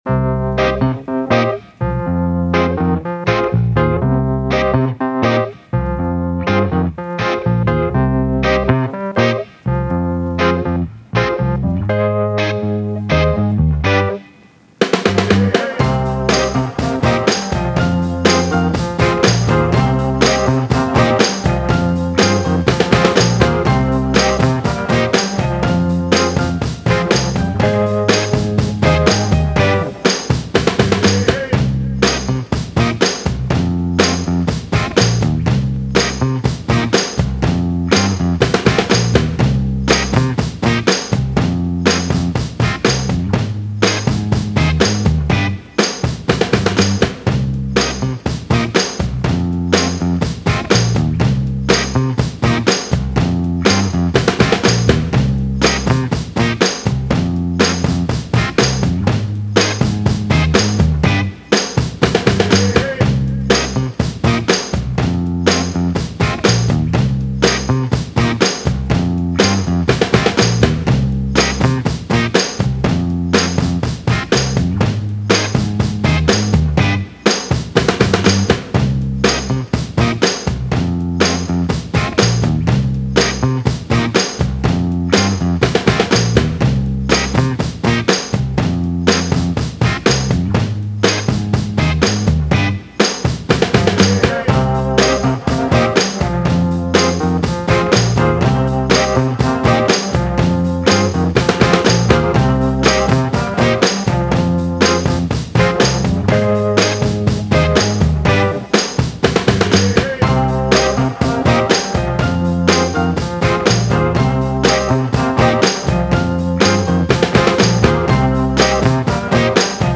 Soul, 60s
C Minor